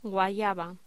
Locución: Guayaba